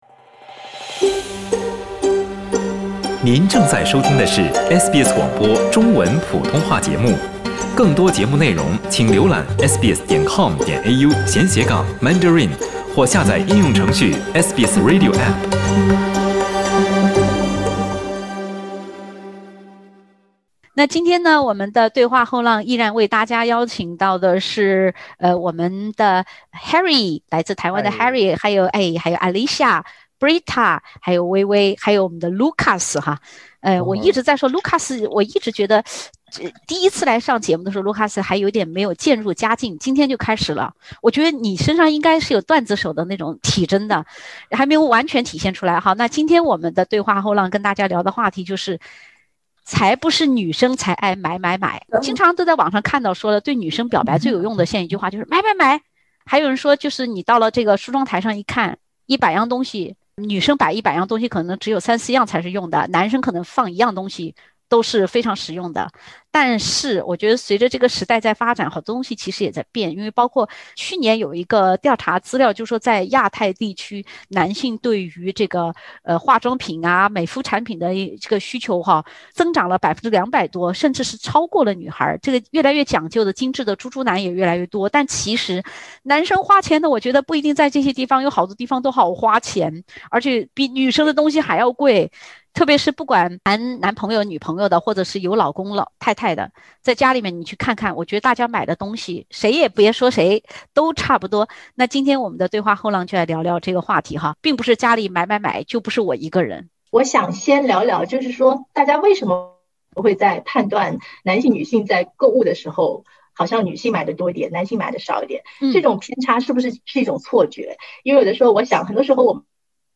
相比一些女性朋友喜欢“包治百病”，其实男生对于自己喜欢的东西花起钱来也是丝毫不心疼。（点击封面图片，收听有趣对话）